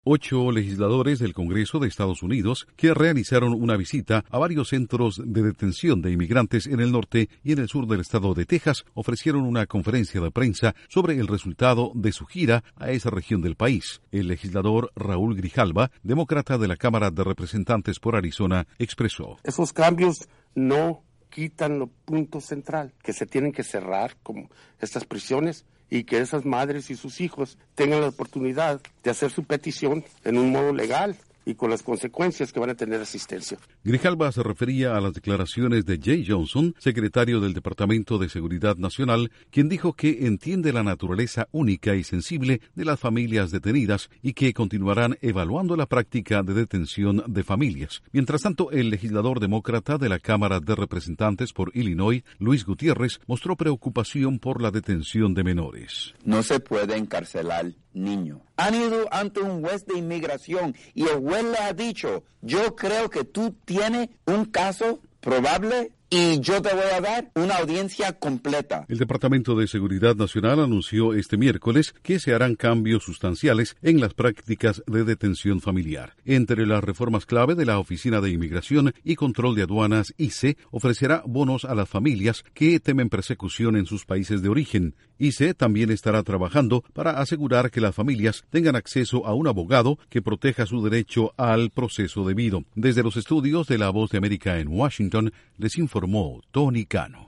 Estados Unidos anuncia cambios en regulaciones de inmigración luego de la visita de legisladores a centros de detención en Texas. Informa desde los estudios de la Voz de América en Washington